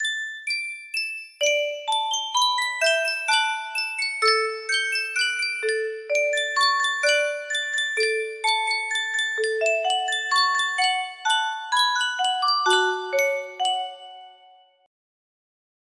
Yunsheng Music Box - Swaziland National Anthem 5024 music box melody
Full range 60